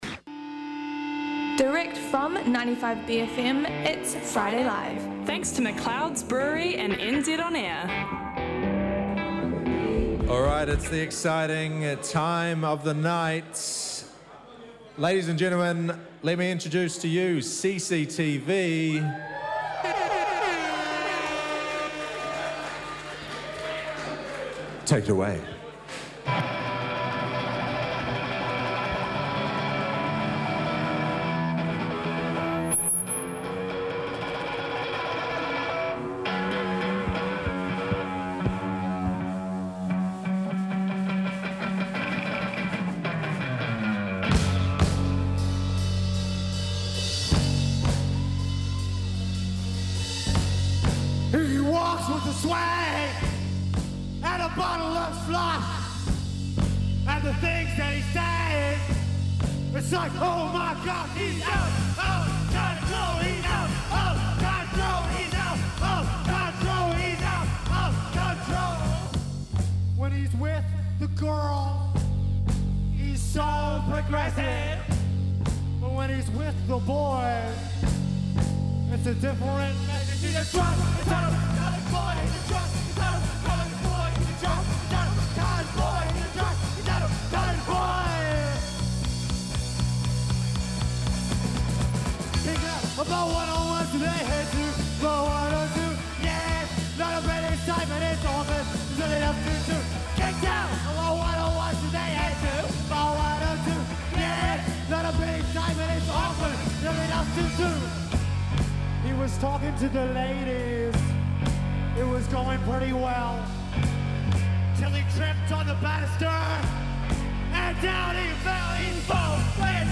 95bFM Drive comes to you live from The Beer Spot! Along with awesome live performances from Wellness and The Grogans!